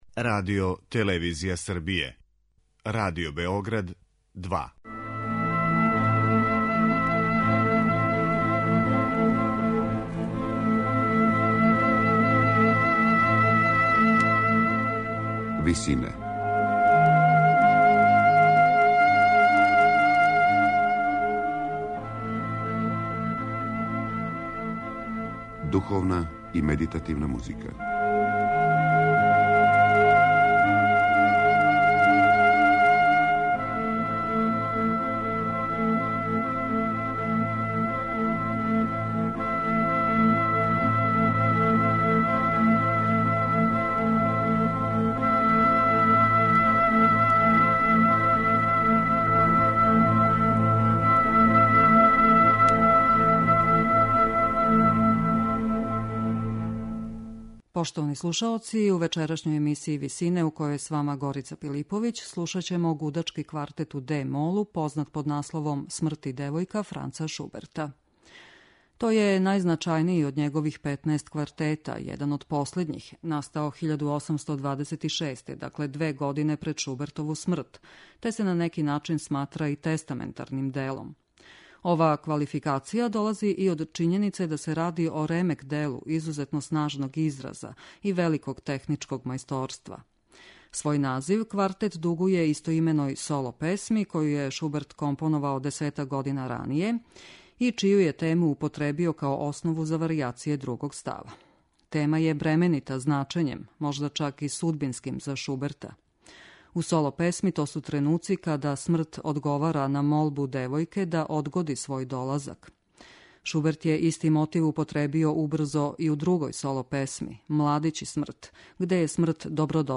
Гудачки квартет 'Смрт и девојка', Франца Шуберта